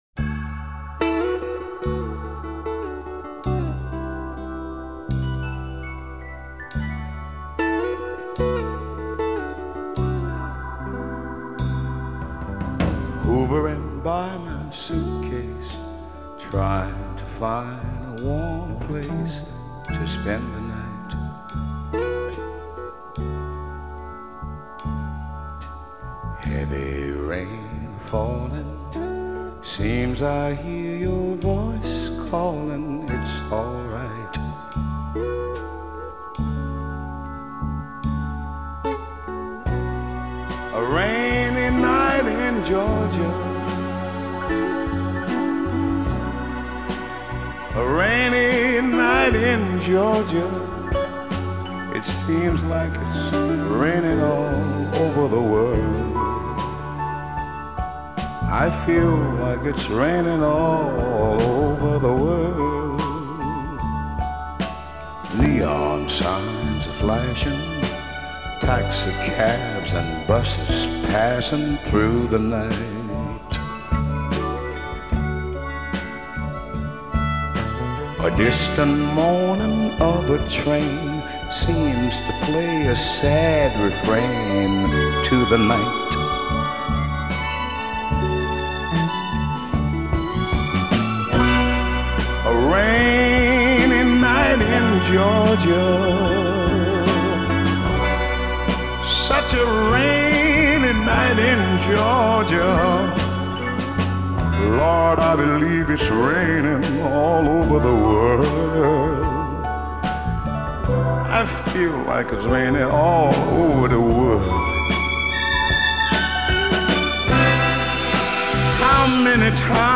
sung with a lot of heart and soul